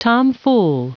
Prononciation du mot tomfool en anglais (fichier audio)
Prononciation du mot : tomfool